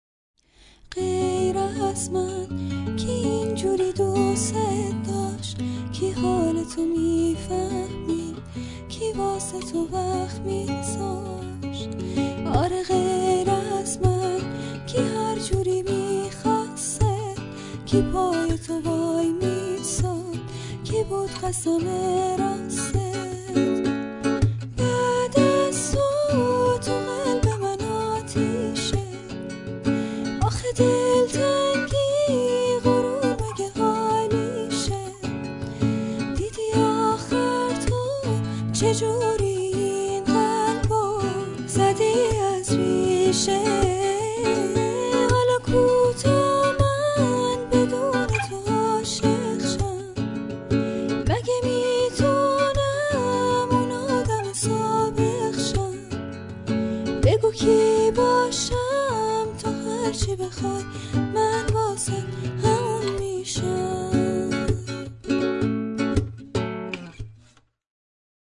با گیتار صدای زن